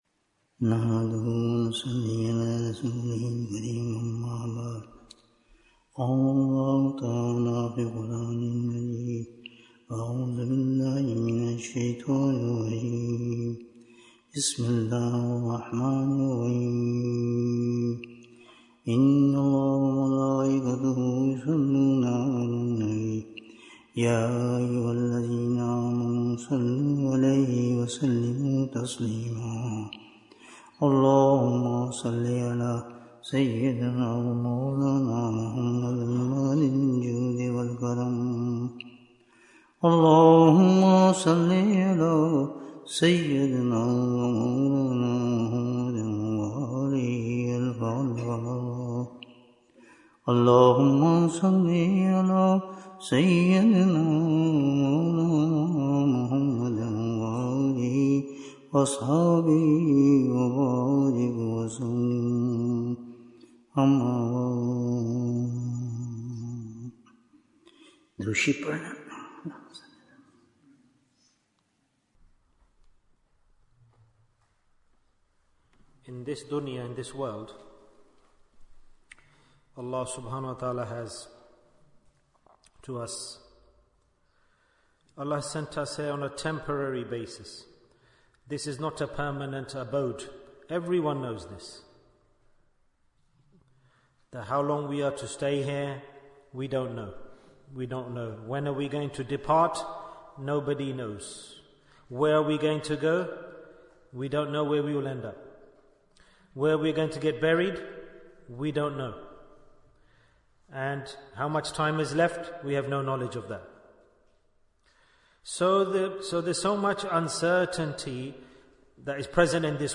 Signs of True Repentance Bayan, 103 minutes21st November, 2024